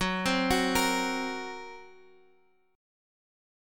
F#Mb5 chord